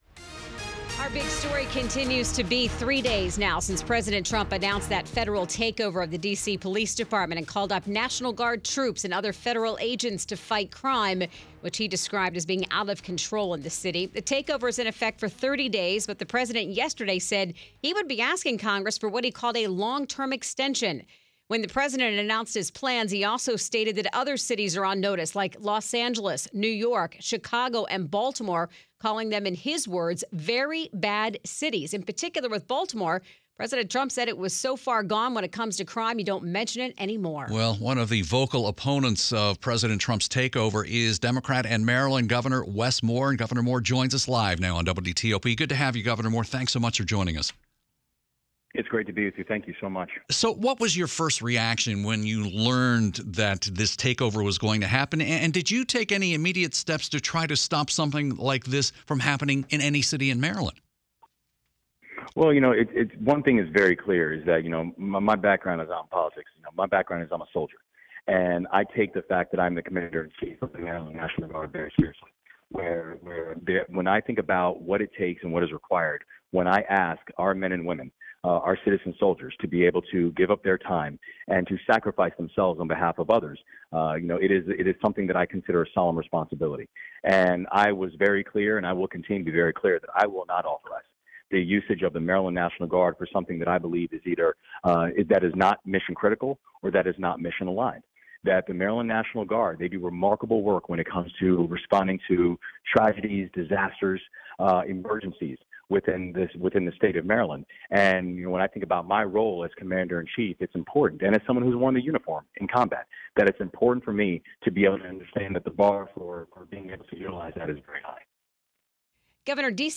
Moore responded directly to those comments on WTOP.